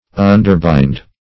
Underbind \Un`der*bind"\